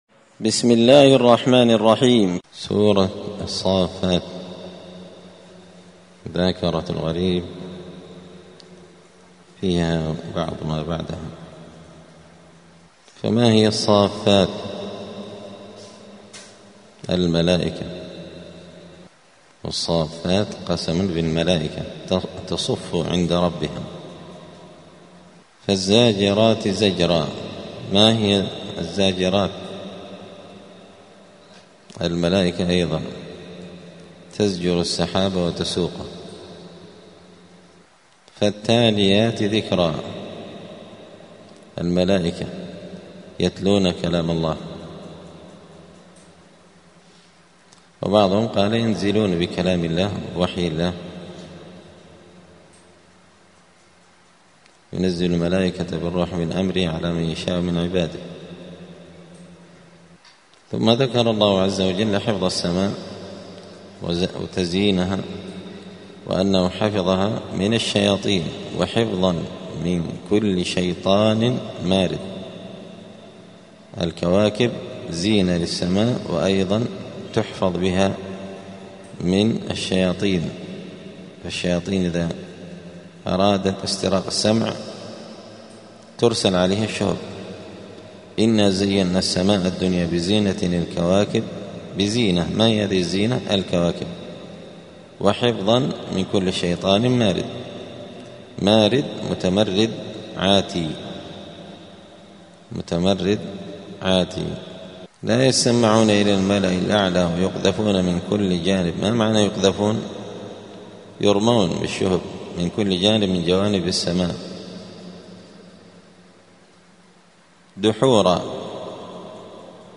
مذاكرة لغريب القرآن في رمضان – الدرس الخامس والعشرون (25) : غريب الجزء الثالث والعشرون.
دار الحديث السلفية بمسجد الفرقان قشن المهرة اليمن